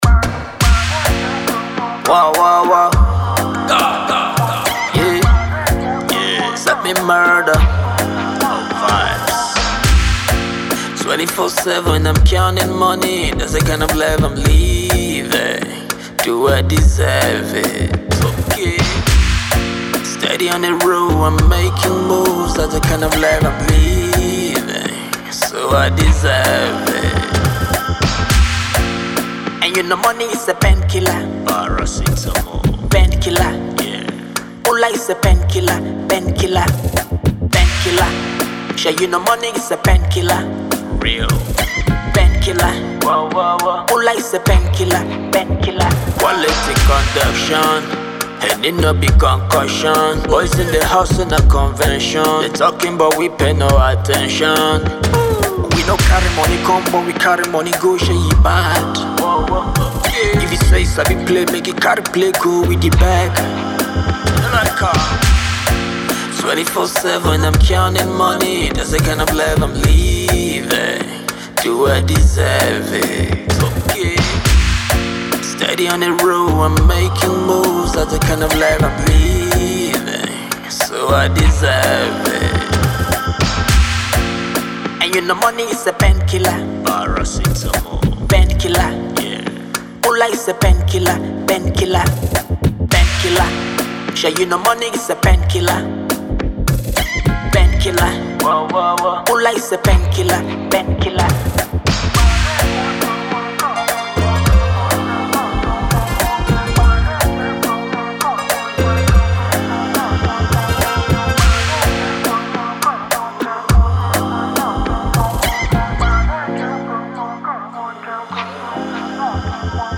Afro Beats song
the hoars voice of reality
symphonic tune blend